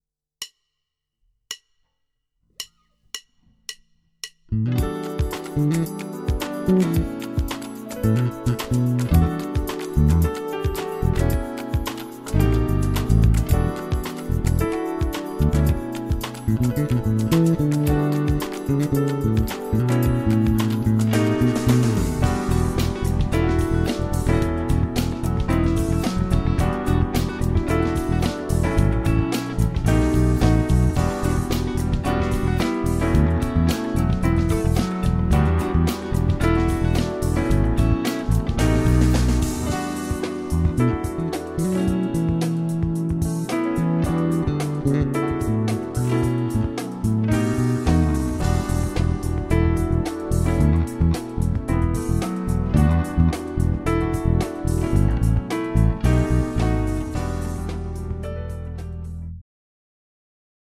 Celkově bych řekl, že se zvuk dostal více do V s artikulovanějšími nižšími středy, ale naprosto zásadní je úplně jiný pocit z nástroje.
Oba snimace